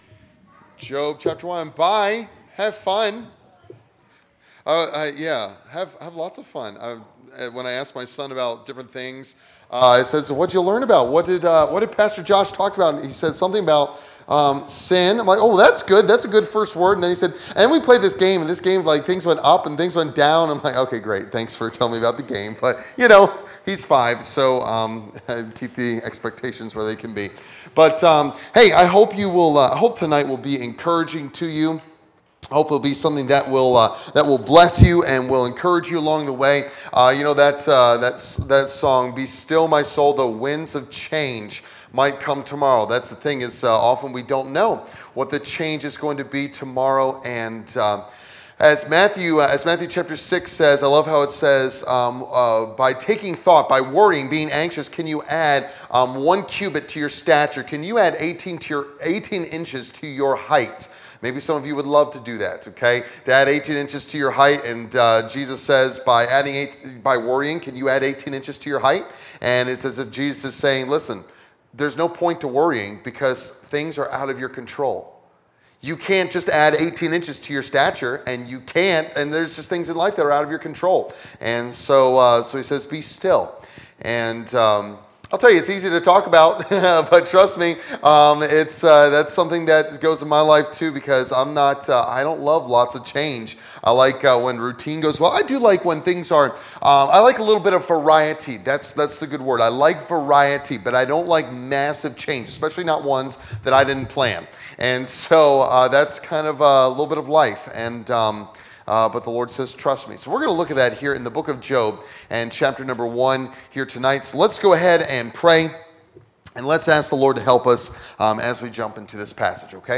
Series: Revival Meeting